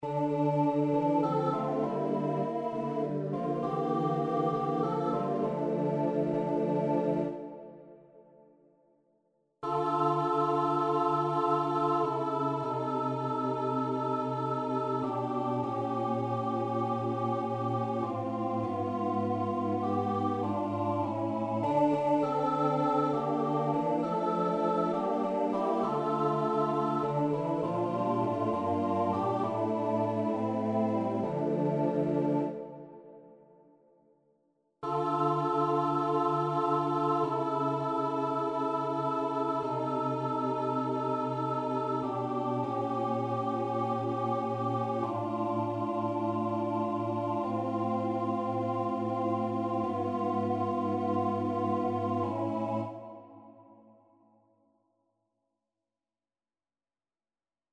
Number of voices: 4vv Voicing: SATB Genre: Sacred, Responsorial Psalm
Language: French Instruments: A cappella